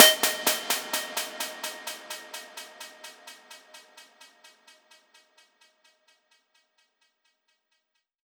VES2 FX Impact 14.wav